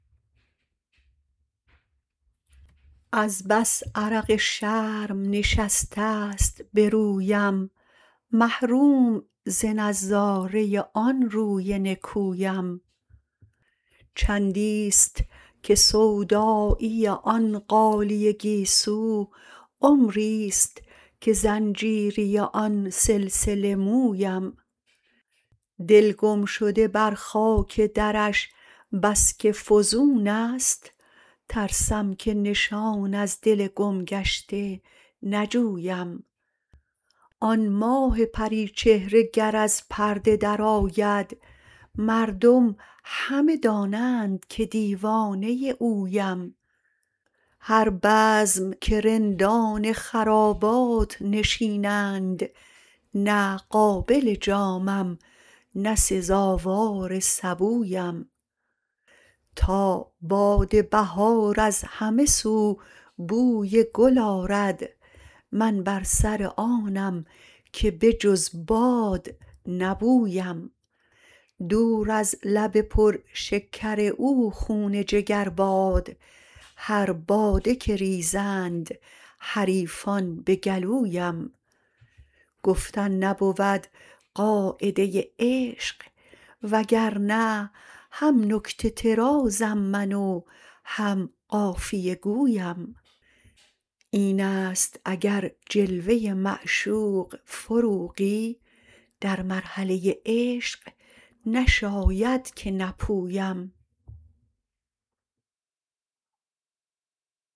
خوانش